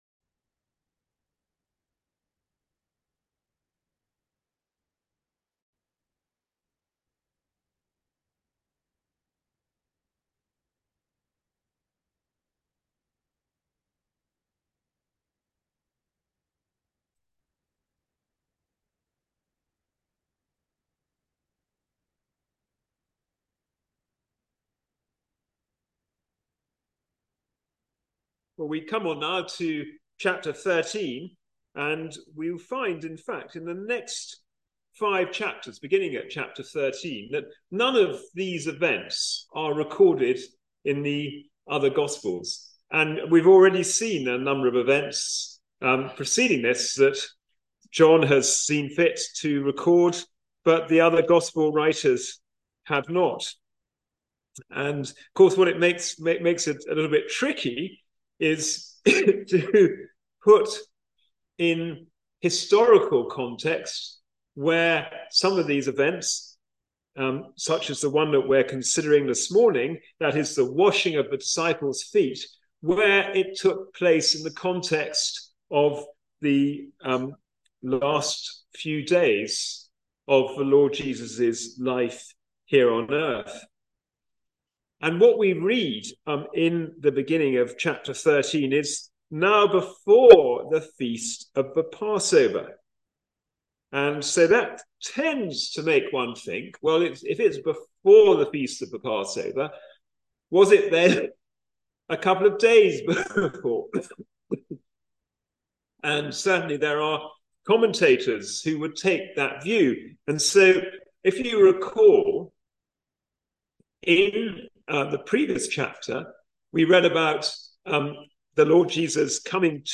Passage: John 13:1-2 Service Type: Sunday Morning Service Jesus Washing his disciples Feet